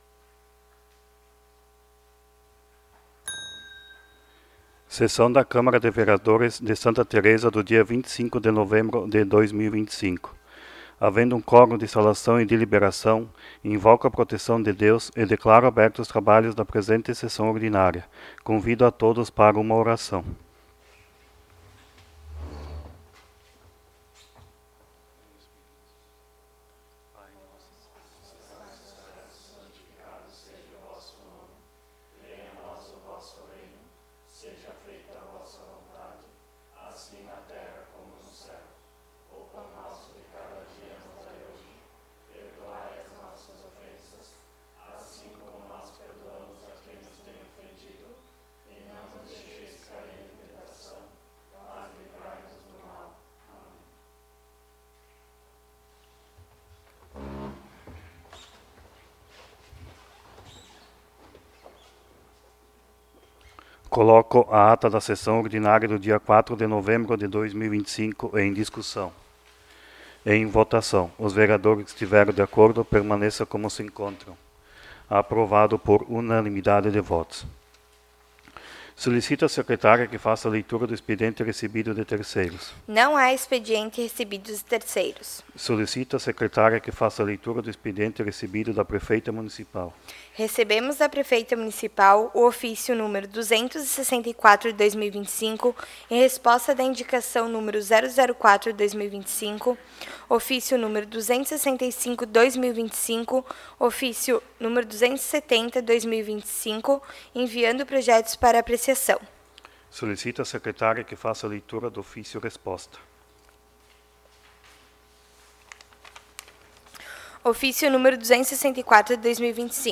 20° Sessão Ordinária de 2025
Áudio da Sessão